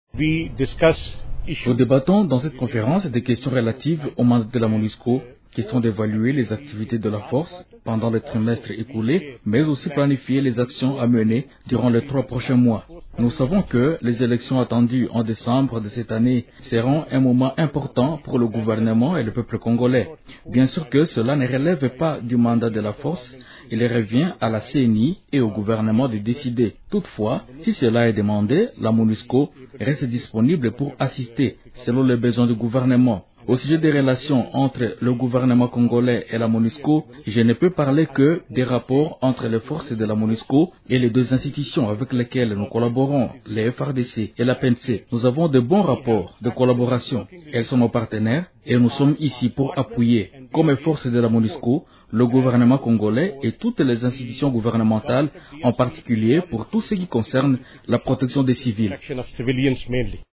Le général Muhammad Mughal a fait cette déclaration lors de l’ouverture, par le haut commandement des forces de la MONUSCO, de sa conférence trimestrielle.